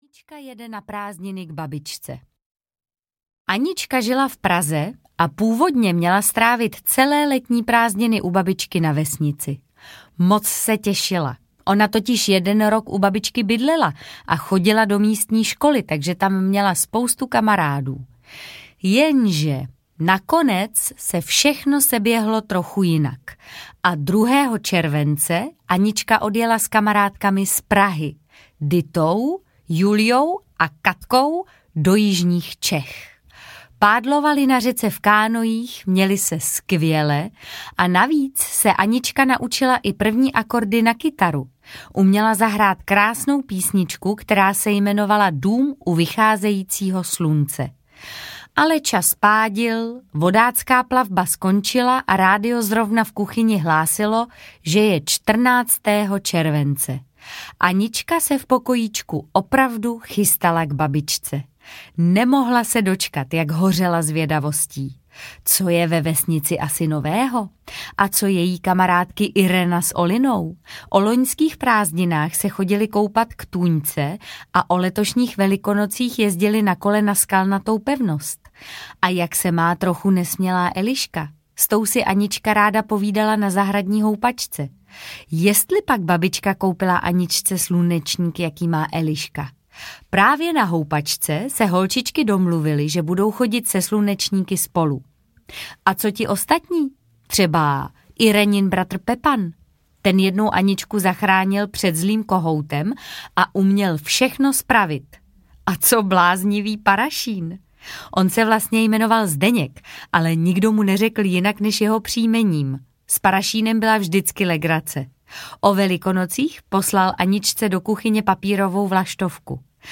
Anička v zahradě audiokniha
Ukázka z knihy
• InterpretMartha Issová